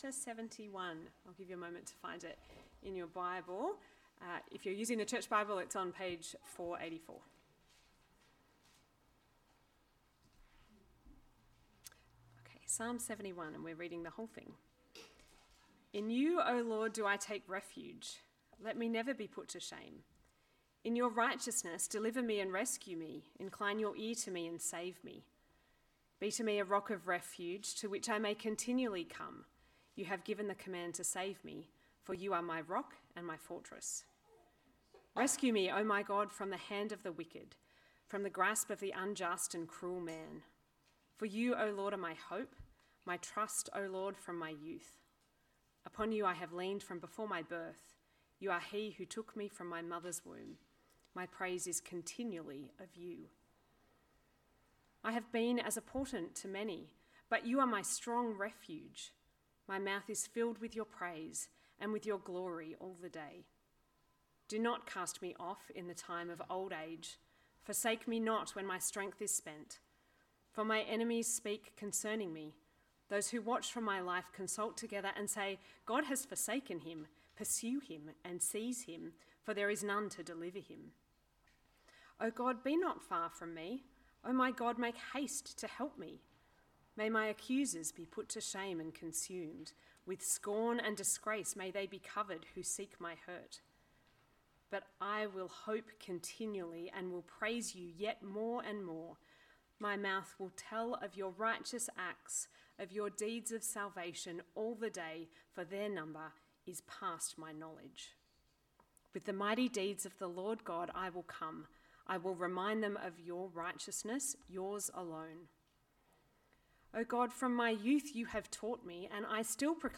… continue reading 358 episodes # Christianity # Religion # Anglican # Jesus # Helensburgh # Stanwell # Park # Helensburgh Stanwell Park Anglican A Church # Stanwell Park Anglican A Church # Sermons